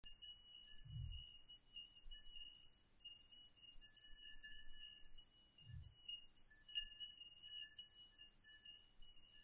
Windspiel „Flores“, 5 Klangröhren
mit Bambusstab und 5 Klangröhren aus Aluminium
Dieses wunderschöne Klangspiel vereint Bambus und Aluminium zu einer harmonischen Poesie aus liebreizender Optik und meditativer Akustik.
Klangbeispiel